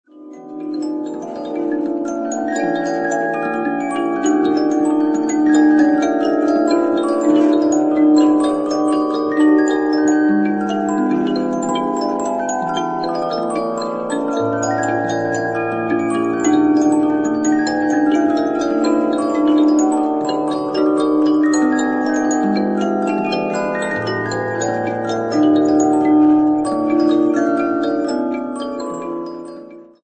Мелодия вальса «Mabel» Дэниела Годфри (начало)